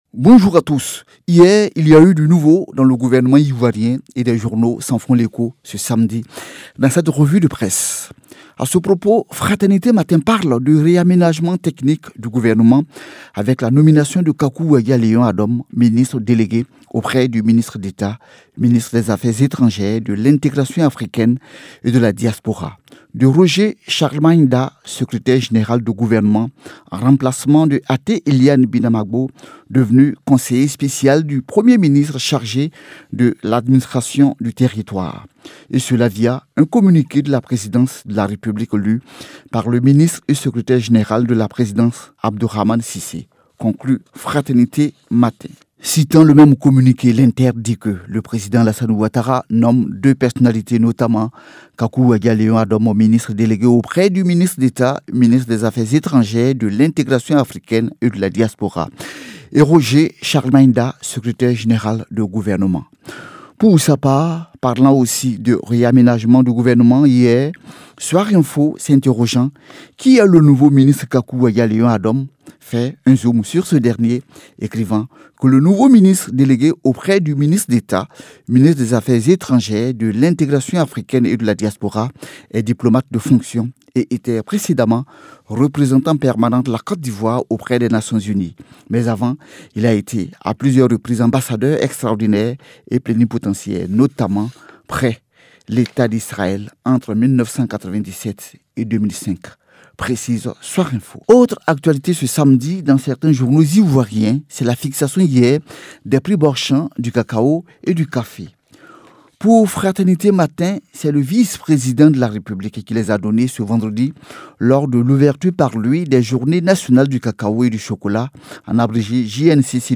revue-de-presse-du-1er-octobre-2022.mp3